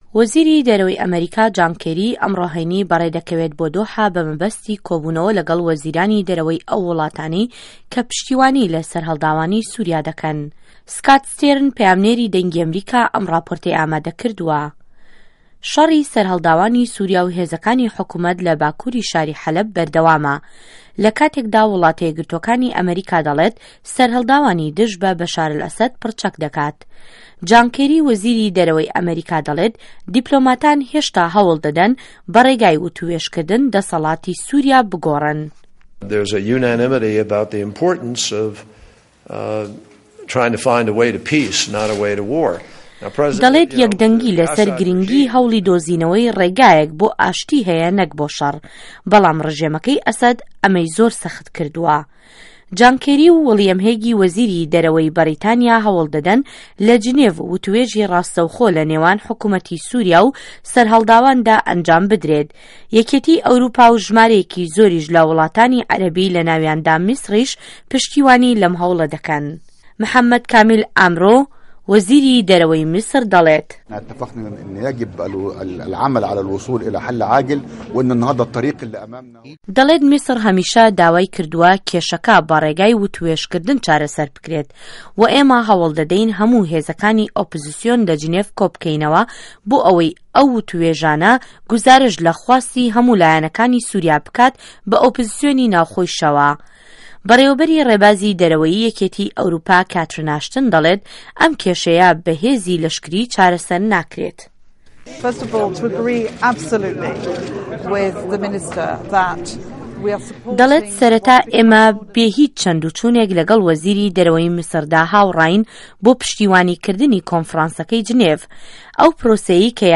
وتووێژی دۆستانی سوریا